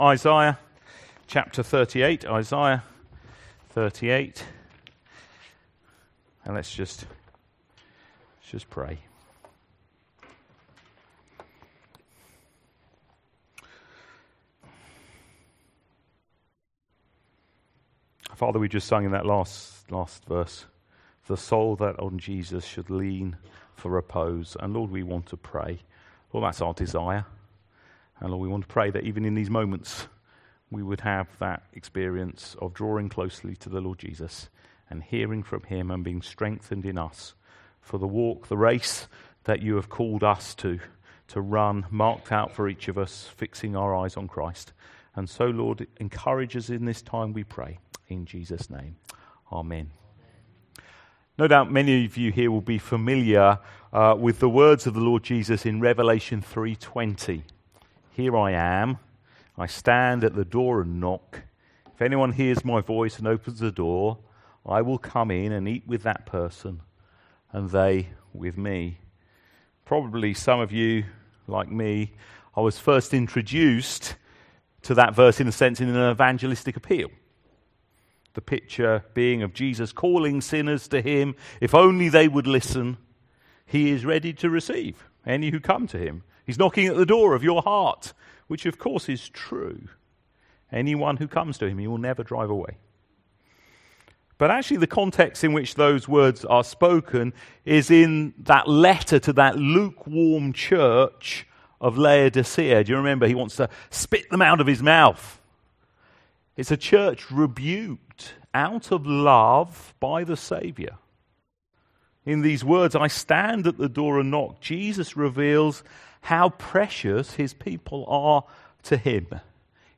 LABC Sermons Isaiah 38v4-6 God's ministry in our struggles Play Episode Pause Episode Mute/Unmute Episode Rewind 10 Seconds 1x Fast Forward 30 seconds 00:00 / 33:25 Subscribe Share RSS Feed Share Link Embed